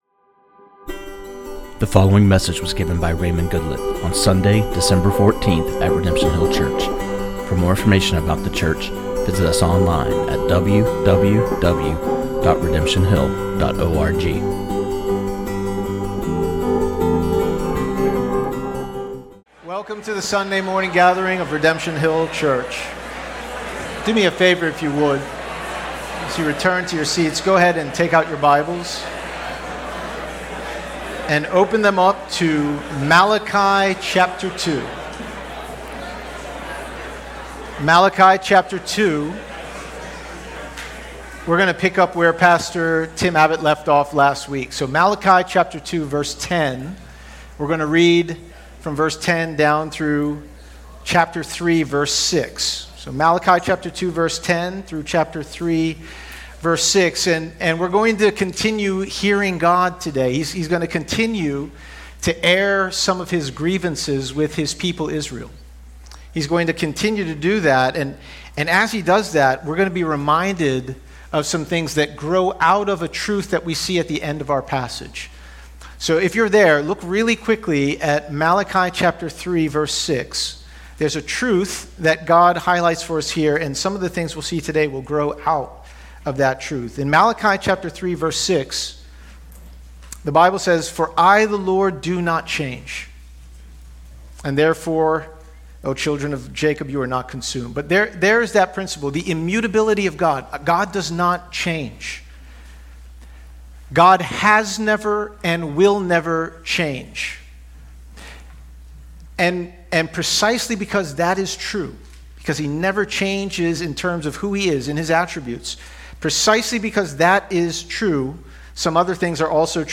This sermon on Malachi 2:10-3:5